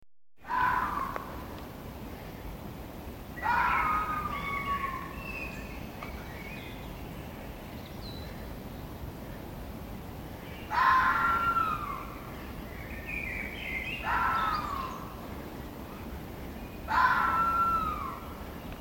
Sound Effects
Fox Scream In The Forest